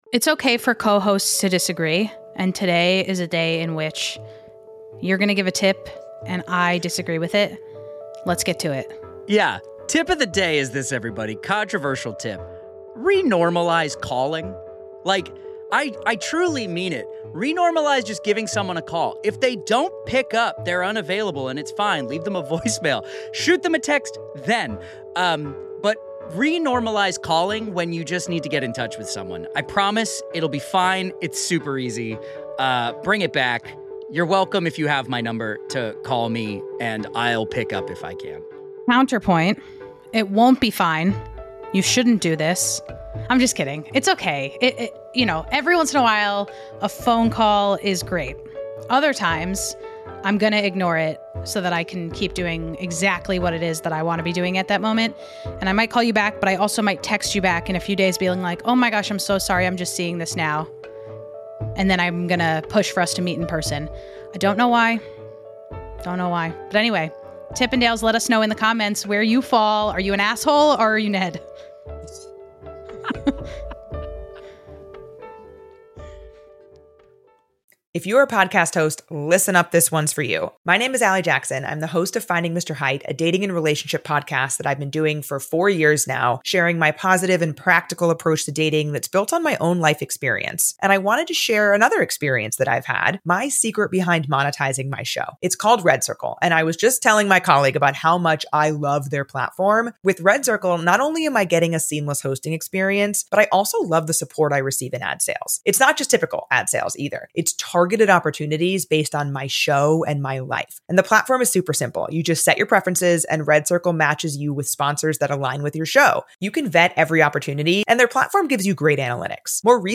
This lighthearted debate highlights the changing social norms around communication in our digital age.